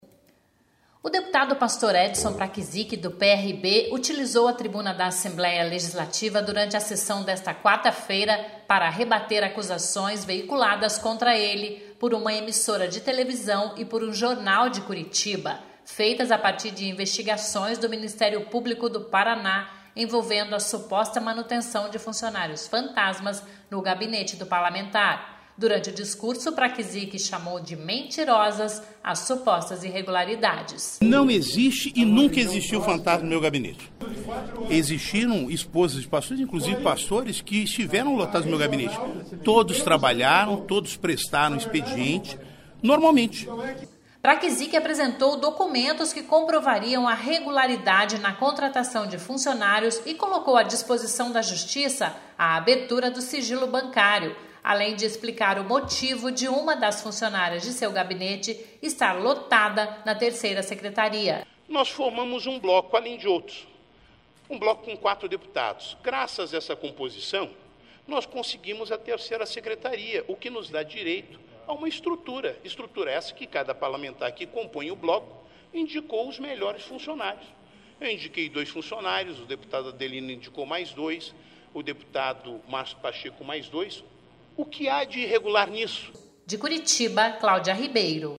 O deputado Pastor Edson Praczyk, do PRB,  utilizou a tribuna da Assembleia Legislativa durante a sessão desta quarta-feira  para rebater  acusações veiculadas contra ele por uma emissora de televisão  por um jornal de Curitiba, feitas  a partir de investigações  do Ministério Público do Paraná, envolvendo a suposta manutenção de funcionários fantasmas no  gabinete do parlamentar.
(sonora:)